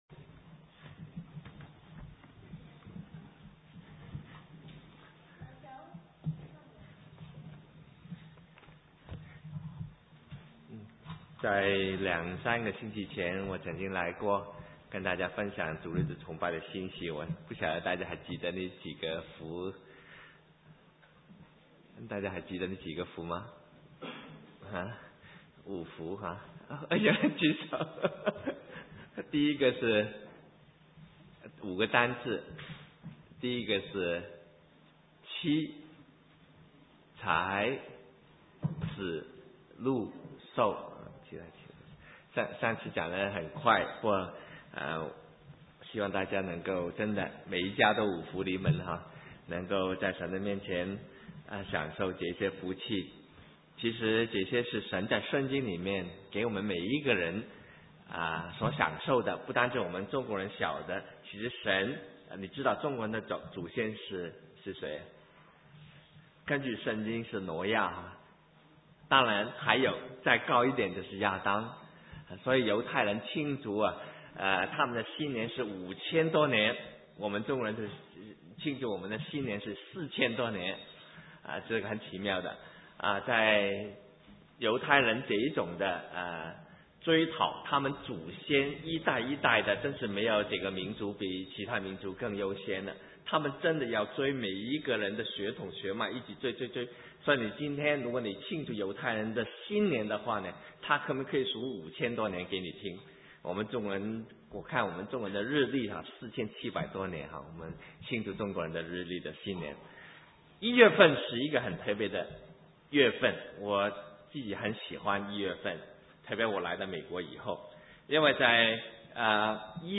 神州宣教--讲道录音 浏览：教会灵力全面观(2006-12-31)